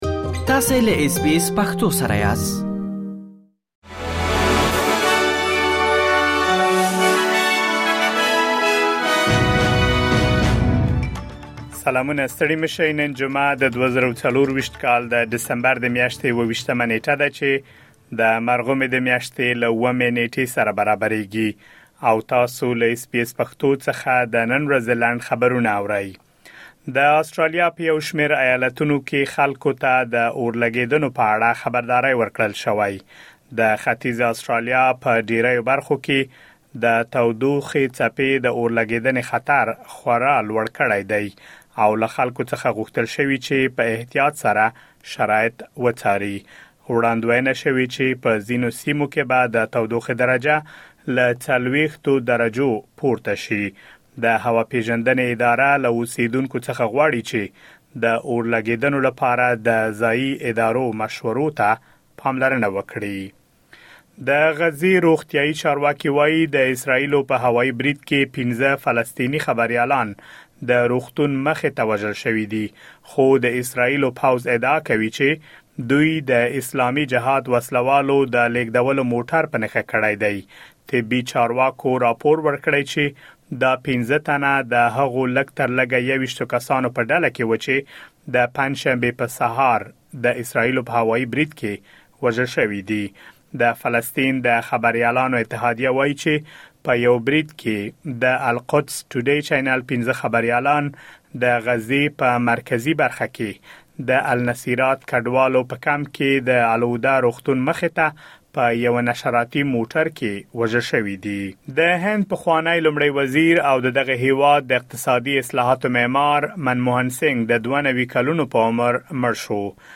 د اس بي اس پښتو د نن ورځې لنډ خبرونه |۲۷ ډسمبر ۲۰۲۴